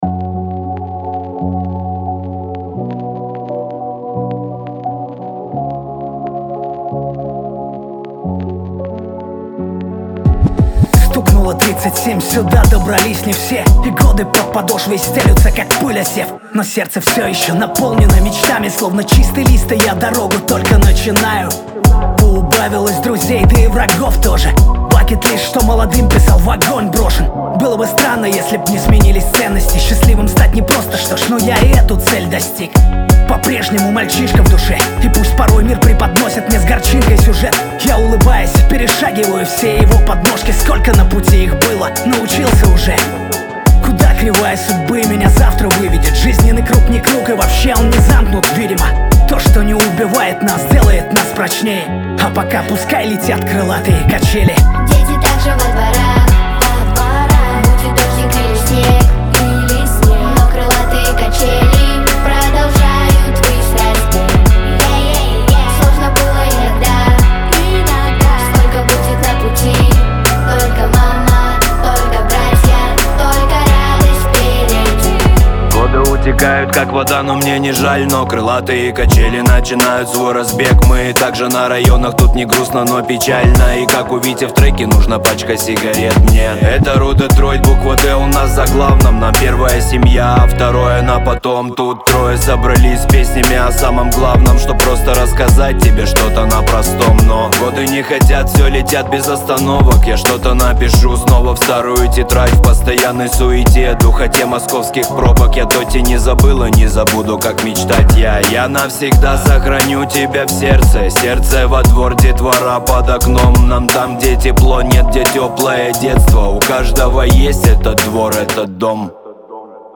Жанр: rusrap